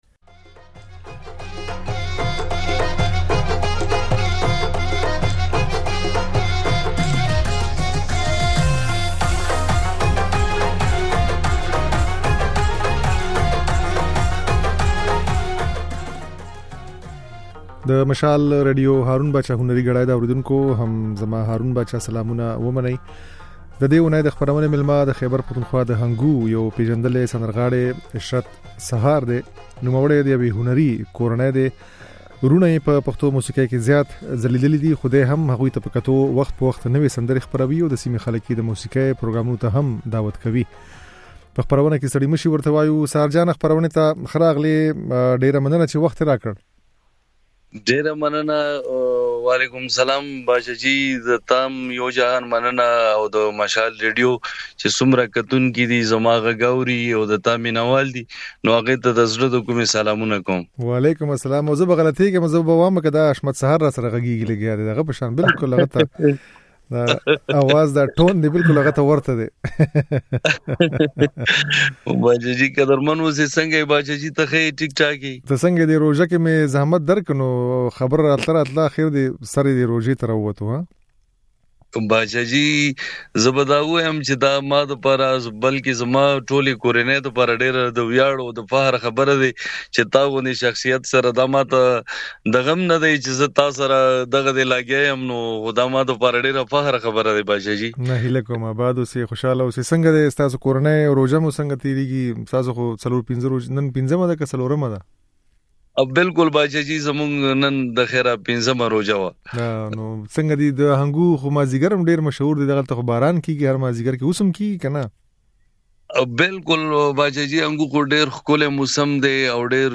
د نوموړي دا خبرې او ځينې سندرې يې په خپرونه کې اورېدای شئ.